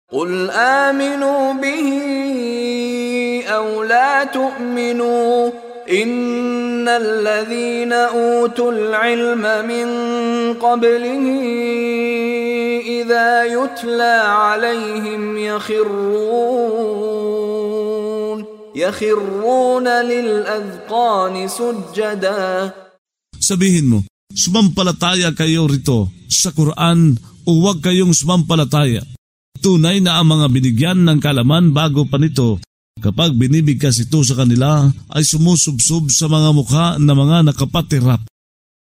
Pagbabasa ng audio sa Filipino (Tagalog) ng mga kahulugan ng Surah Al-Isra ( Ang Gabing Paglalakbay ) na hinati sa mga taludtod, na sinasabayan ng pagbigkas ng reciter na si Mishari bin Rashid Al-Afasy.